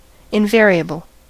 Ääntäminen
IPA: [ɛ̃.va.ʁjabl]